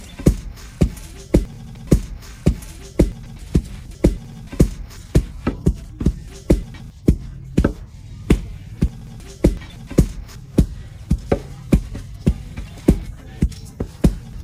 Tiếng Chày giả gạo bằng tay
Thể loại: Tiếng động
Description: Tiếng chày giã gạo bằng tay vang, tiếng giã gạo, tiếng chày nện cối... vọng giữa núi rừng Tây Nguyên – sột soạt, thình thịch, nhịp nhàng như nhịp tim của bản làng.
tieng-chay-gia-gao-bang-tay-www_tiengdong_com.mp3